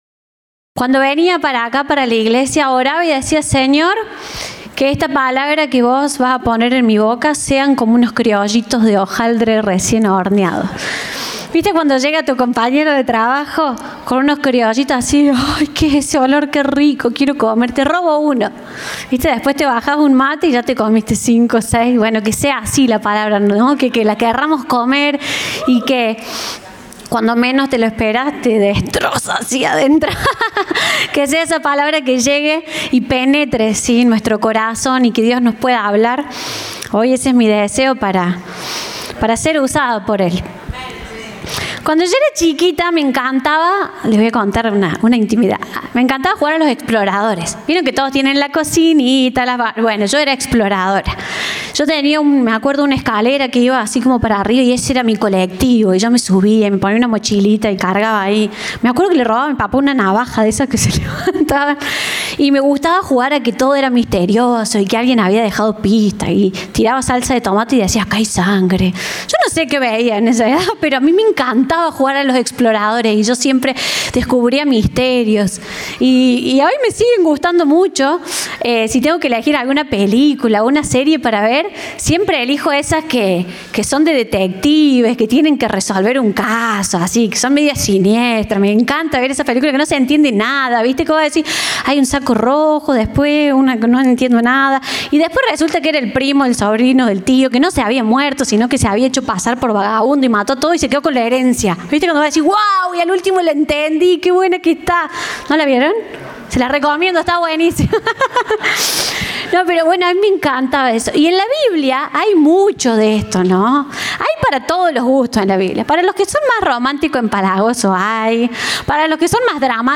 Compartimos el mensaje del Domingo 1 de Diciembre de 2024 Orador invitado